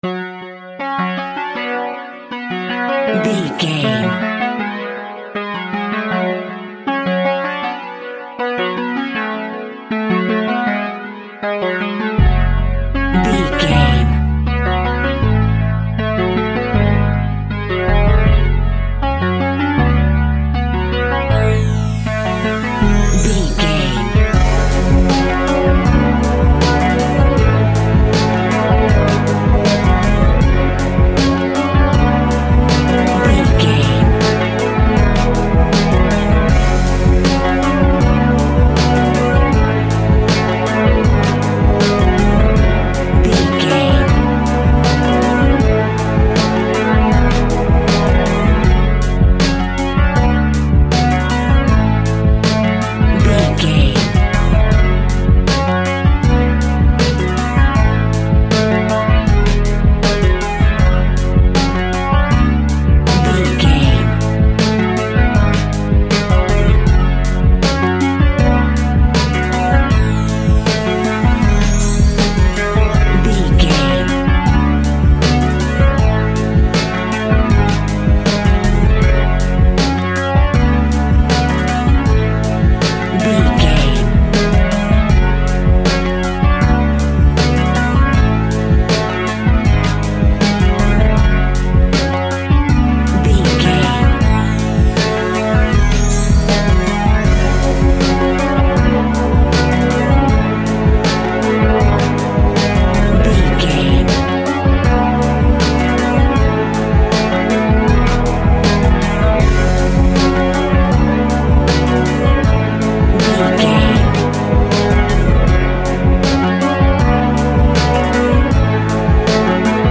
Aeolian/Minor
F#
instrumentals
chilled
laid back
groove
hip hop drums
hip hop synths
piano
hip hop pads